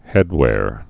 (hĕdwâr)